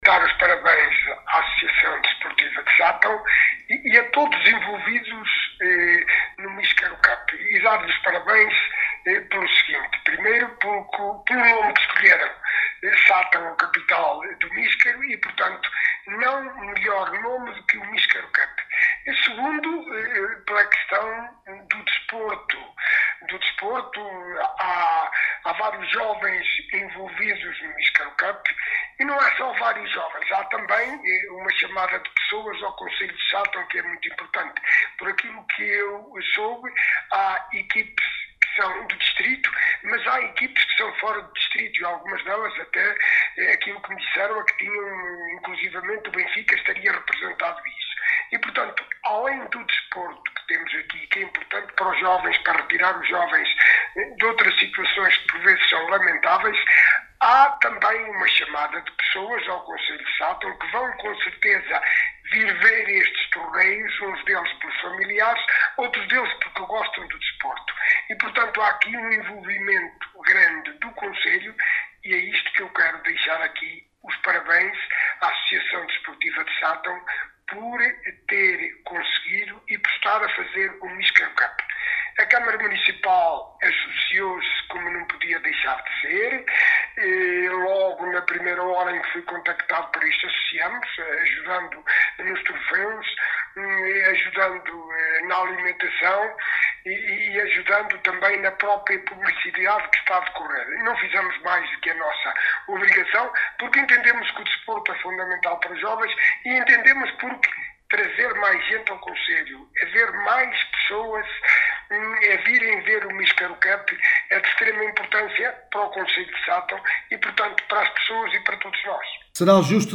Alexandre Vaz, Presidente do Município de Sátão, diz que este Torneio vai trazer até à vila de Sátão muitos visitantes, “um evento em prol do desporto de formação…”.